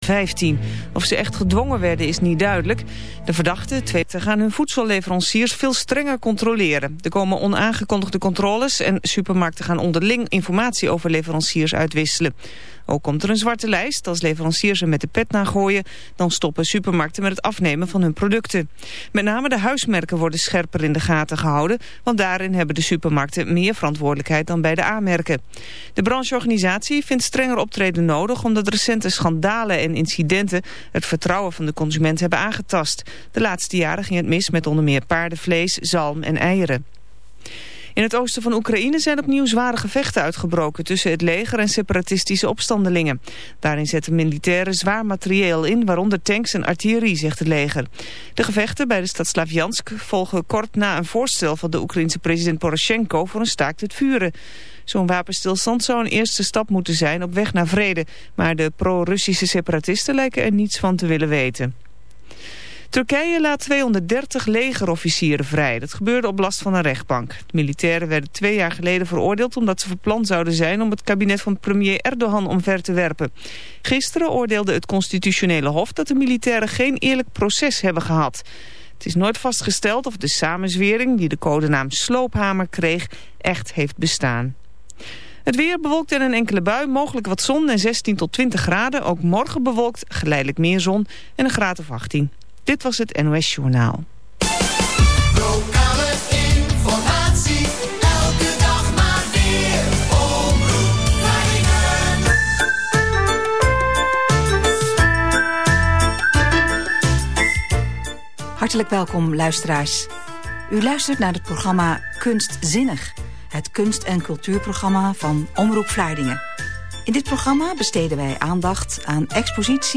omroepvlaardingeninterview19-6-14.mp3